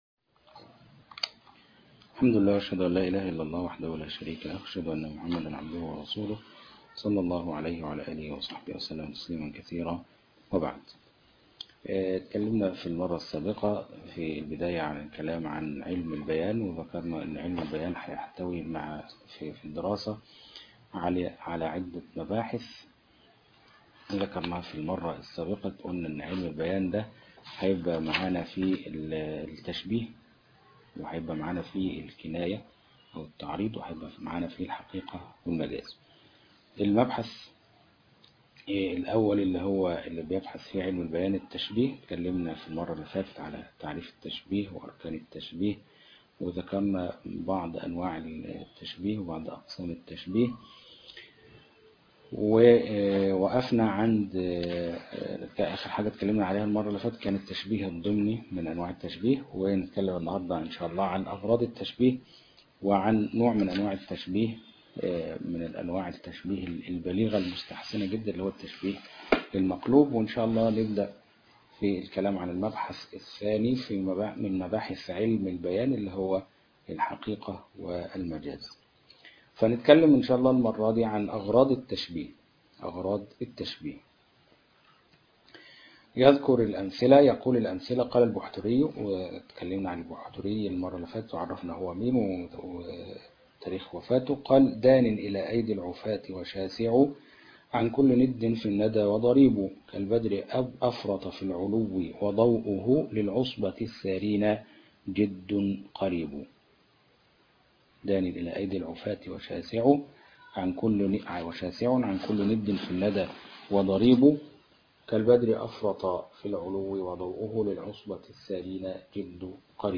شرح كتاب البلاغة الواضحة -3- الفرقة الرابعة - معهد شيخ الإسلام - الشيخ أبو إسحاق الحويني